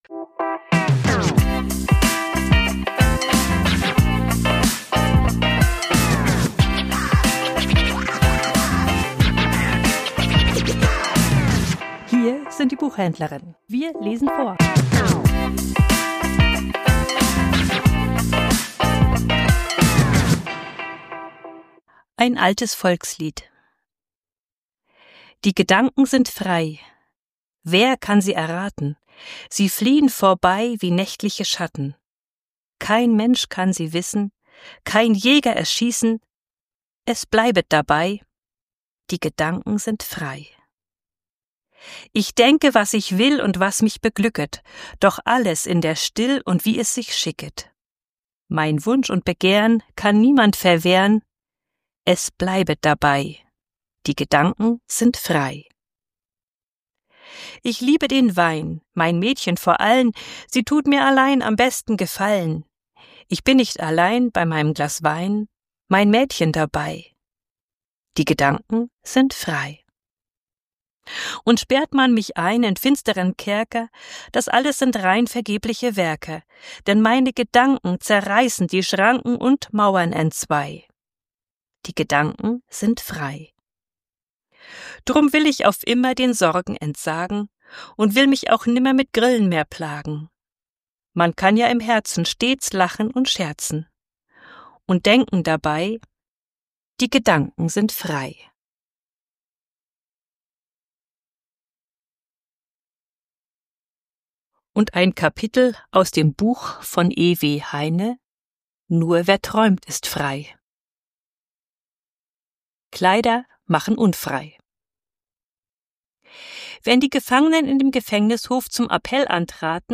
Vorgelesen: Die Gedanken sind frei